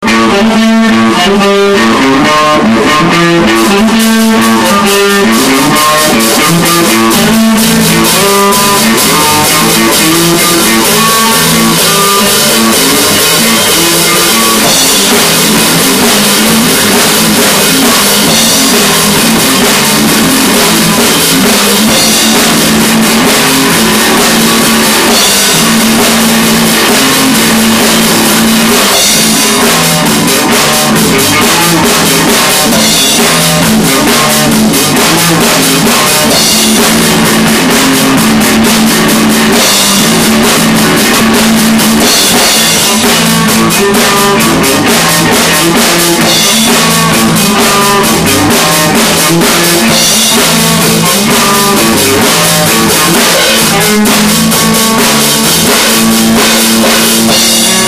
I don't know what kind of introduction would have been proper for our band section... we're a garage band from Palm Harbor. we play rock.
All the material available below was recorded using a Hi8 sony camcorder. the a double ended headphone cable was run to my mic input and sound recorder was used to record from the tape.
Improvised Jam #2 (Full Version)